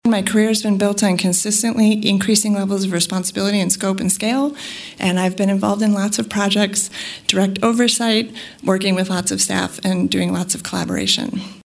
During her interview, Thomas highlighted why she was qualified for the job.